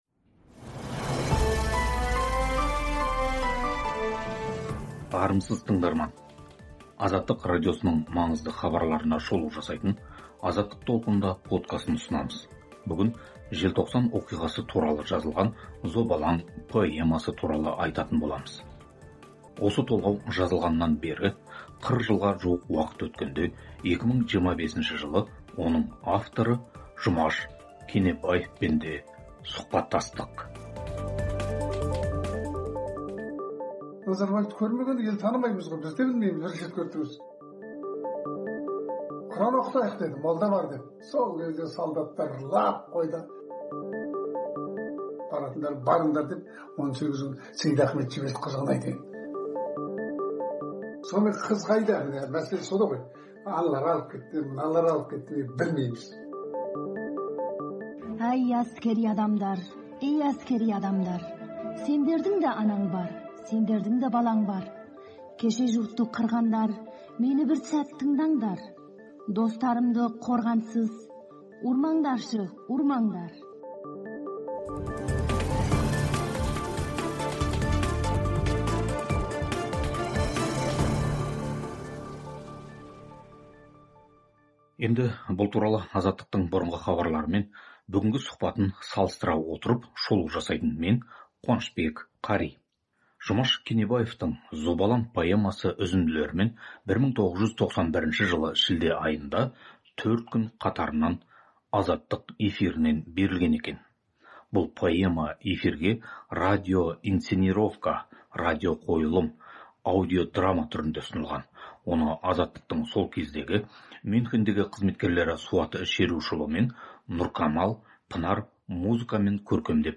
толғауы 1991 жылы Азаттық эфирінен радиоқойылым түрінде берілген.